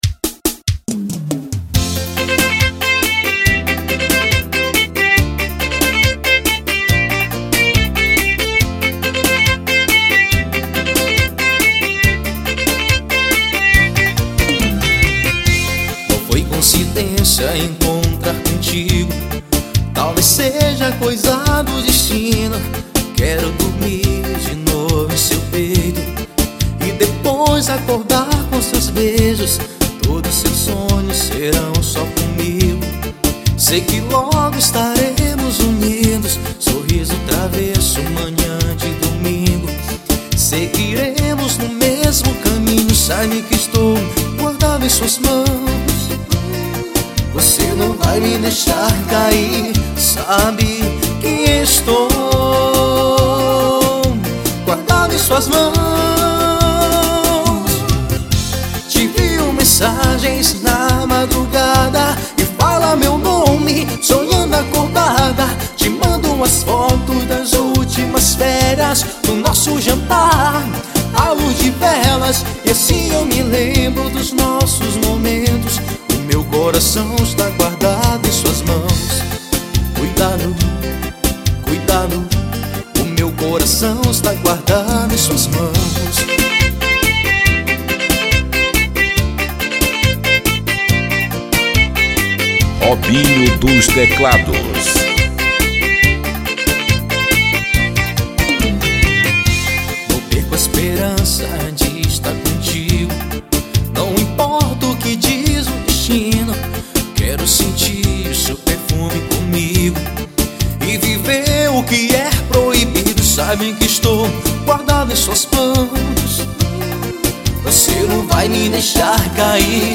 Arrocha.